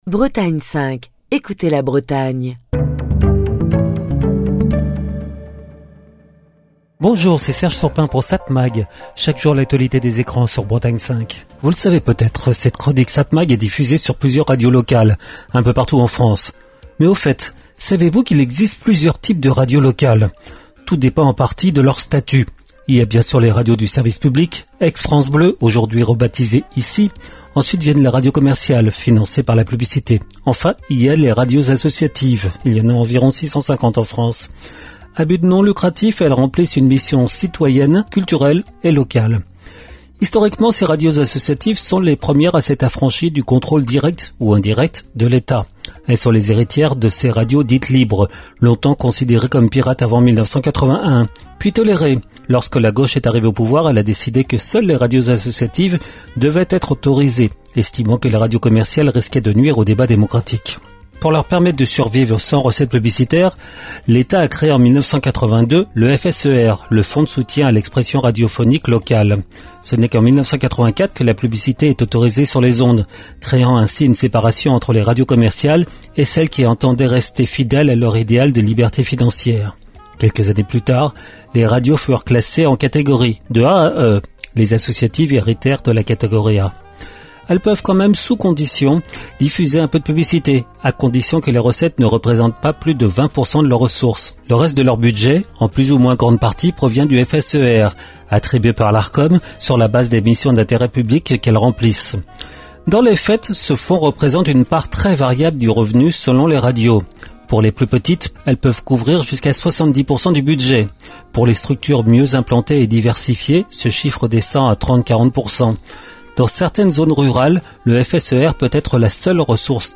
Chronique du 19 mai 2025.